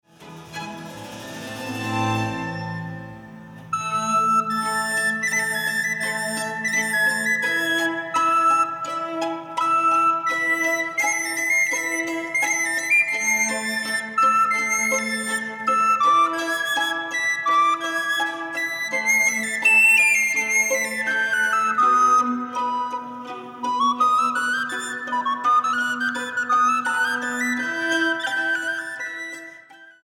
para flauta sopranino, cuerdas y continuo